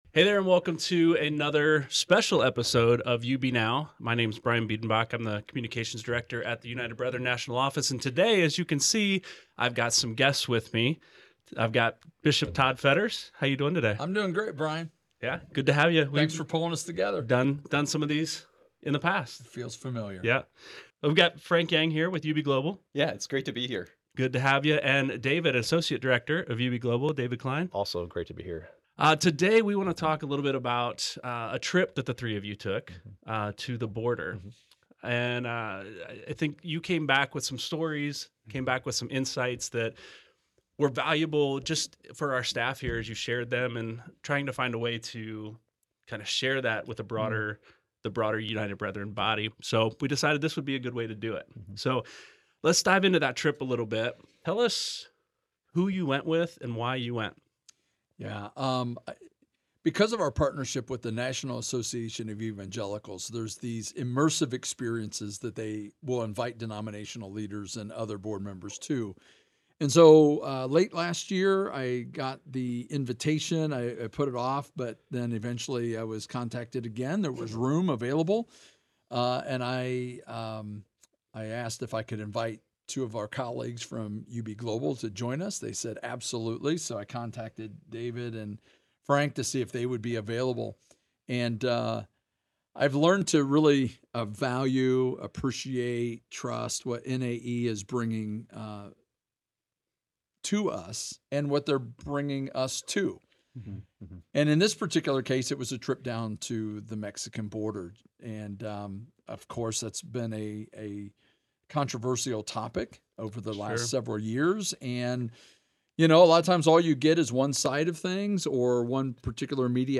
This is a valuable conversation that you will find to be both challenging and encouraging.